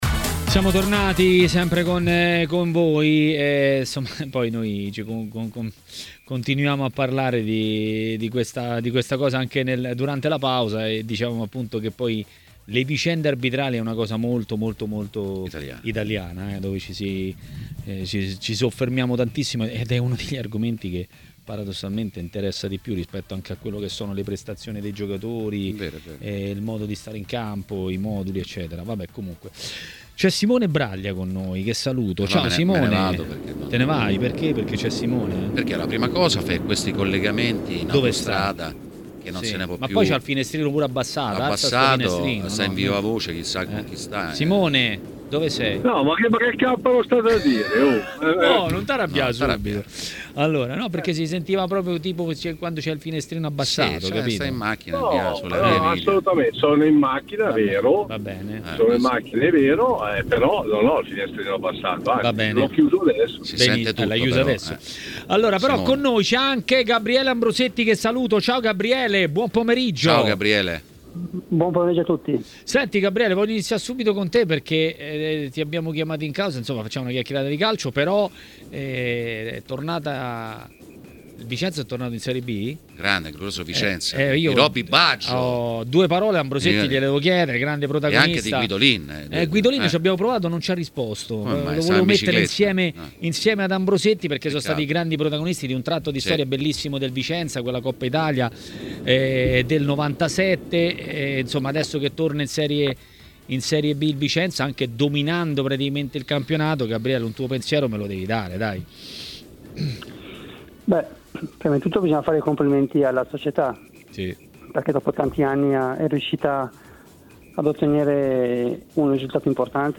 ha parlato ai microfoni di TMW Radio, durante Maracanà.